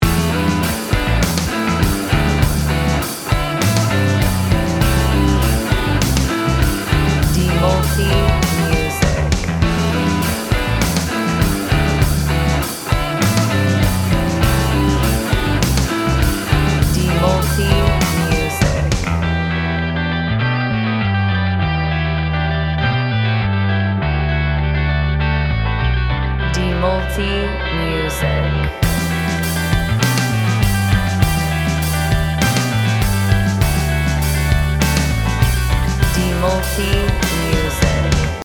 Gym Music Instrumental